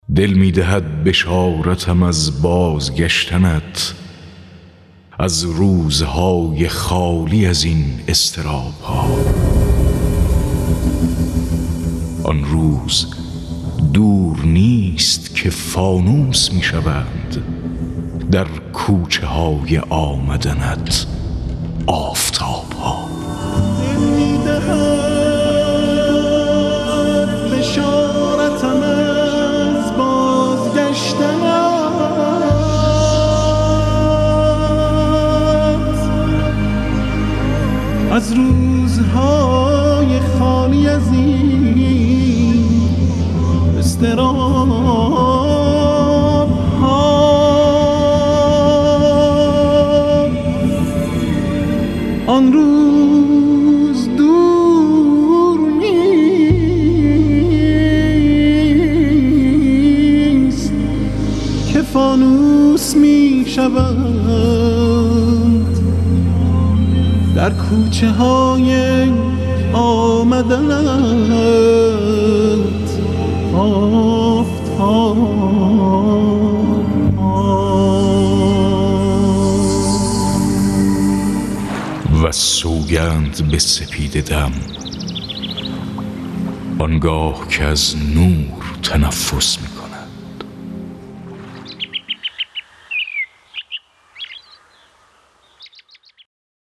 دکلمه‌های مهدوی